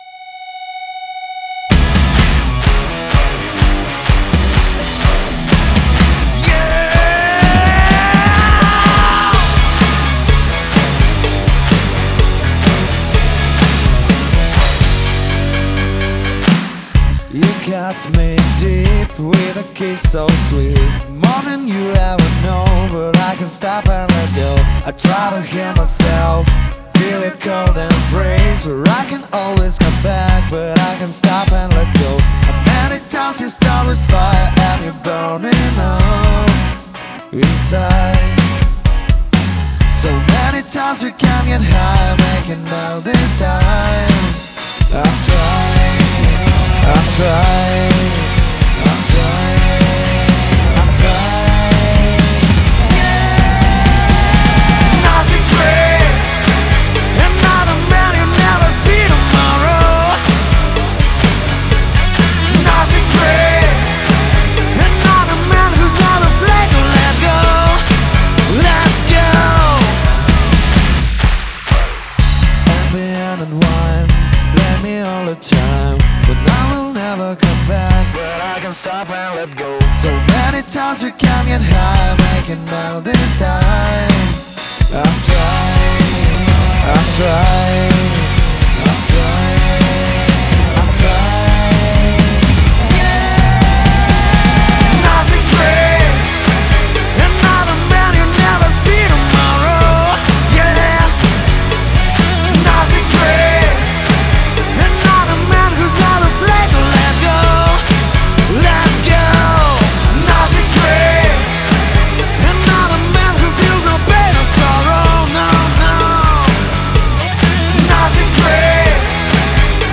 Rock.
Там хет в припевах сыпет сильно, soothe ему нужен ) стоит.